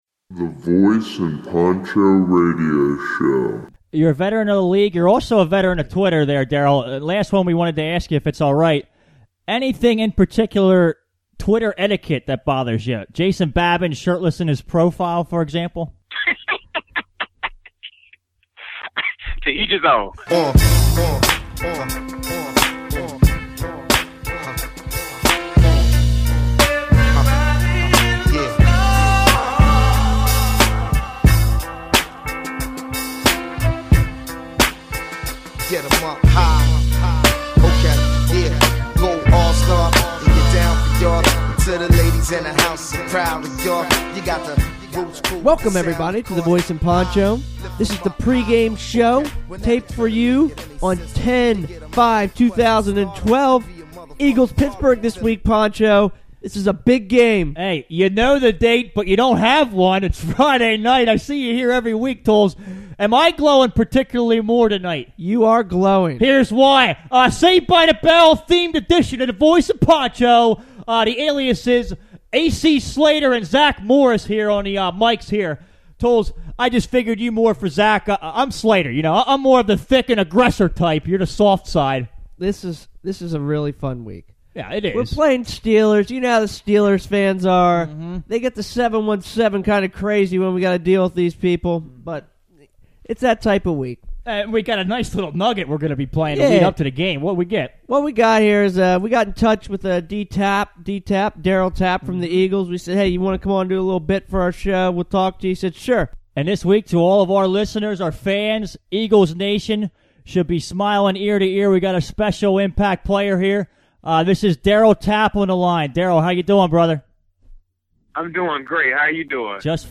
Eagles vs Steelers, Darryl Tapp interview